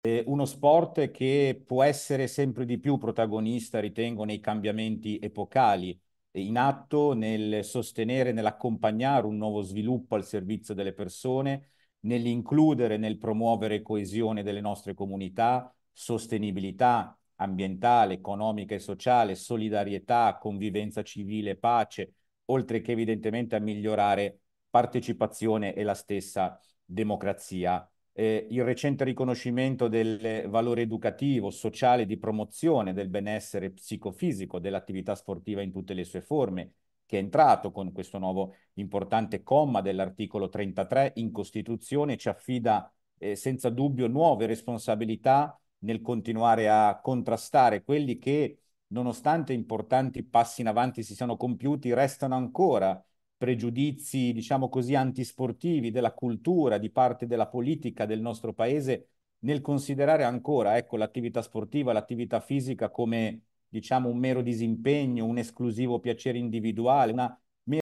Sport sociale e per tutti significa inclusione, salute, socialità. Ascoltiamolo un passaggio del suo intervento.